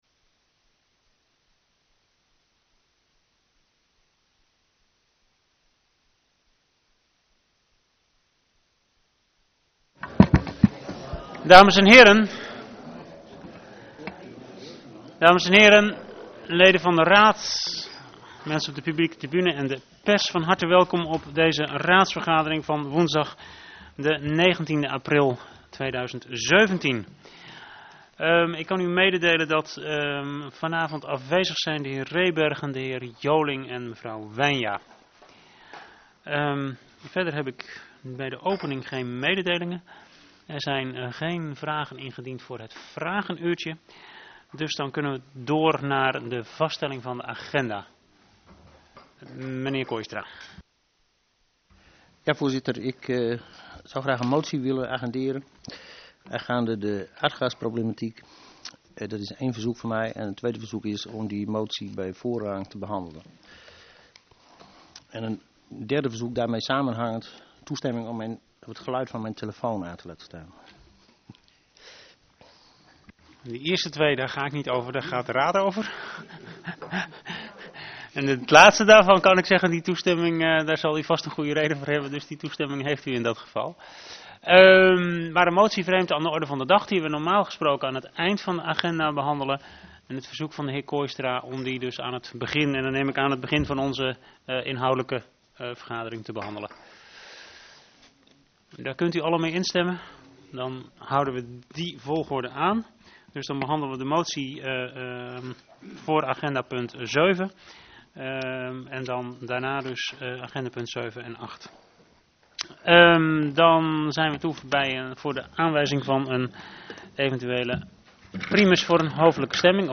Gemeenteraad (Ten Boer) 19 april 2017 20:00:00, Gemeente Groningen
Download de volledige audio van deze vergadering